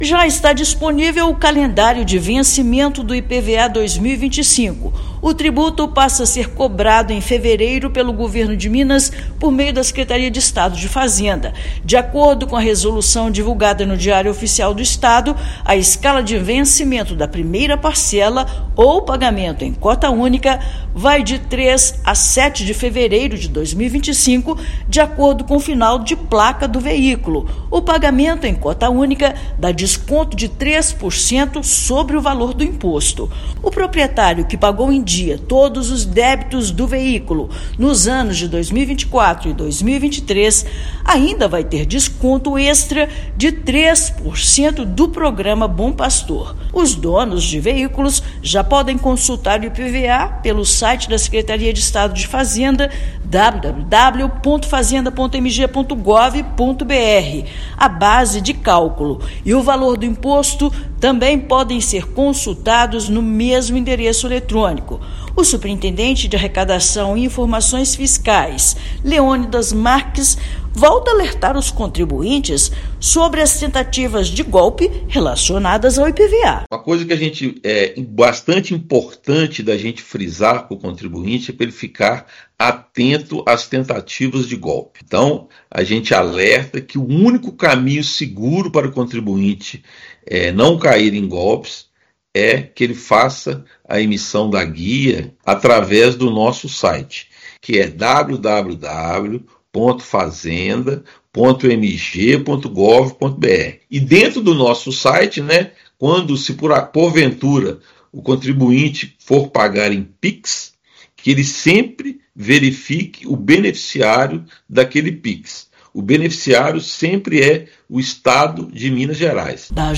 Proprietário que pagou os débitos do veículo em dia em 2024 e 2023 terá desconto de 3% do programa Bom Pagador. Ouça matéria de rádio.